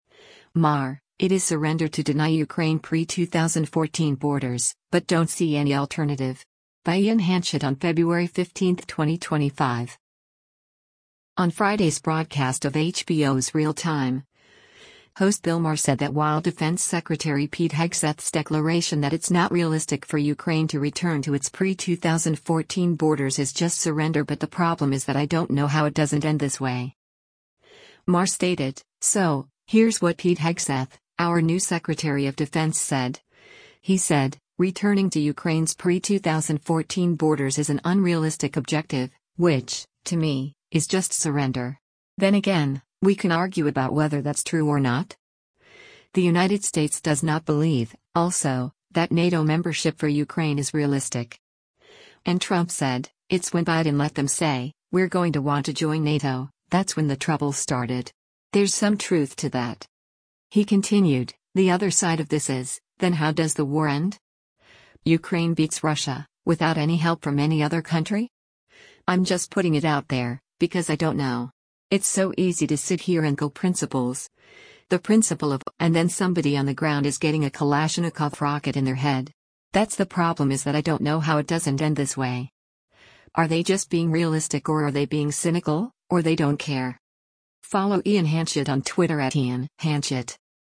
On Friday’s broadcast of HBO’s “Real Time,” host Bill Maher said that while Defense Secretary Pete Hegseth’s declaration that it’s not realistic for Ukraine to return to its pre-2014 borders “is just surrender” but “the problem is that I don’t know how it doesn’t end this way.”